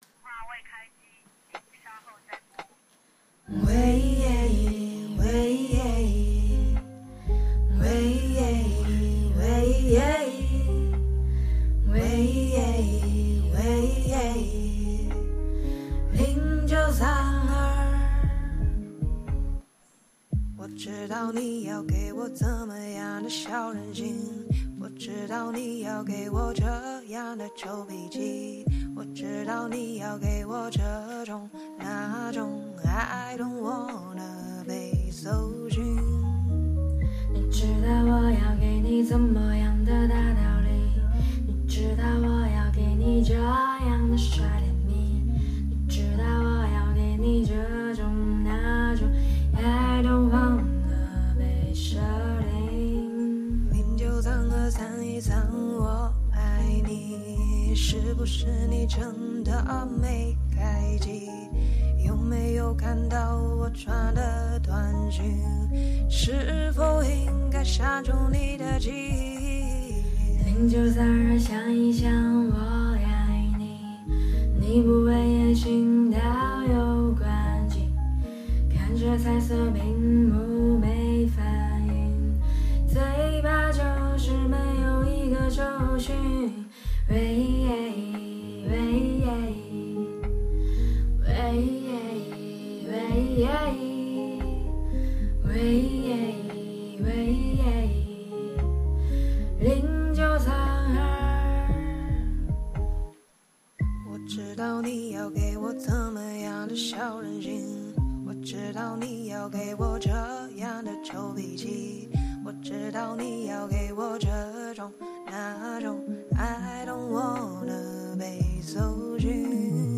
扔下画笔匆匆忙忙就录了😭身体还没完全恢复不该录的 但来不及了 不要嫌弃我！